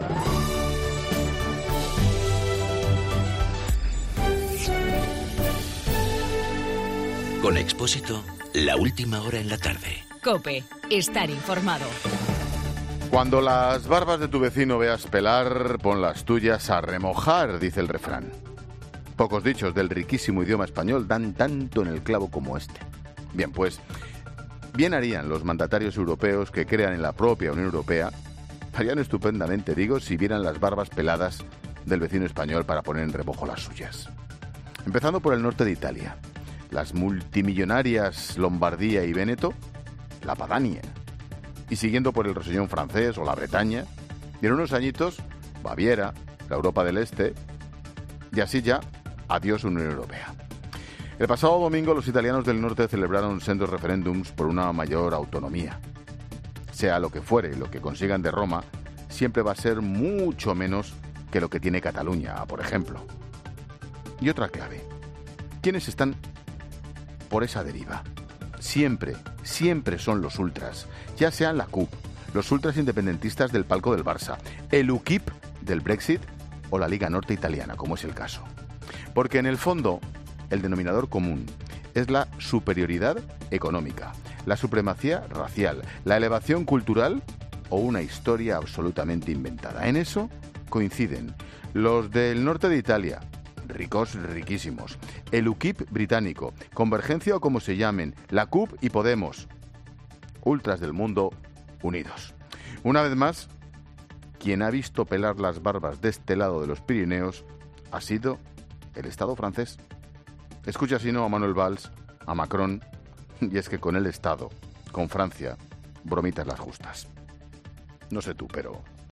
Monólogo de Expósito
El comentario de Ángel Expósito sobre los referendos en Lombardía y Véneto.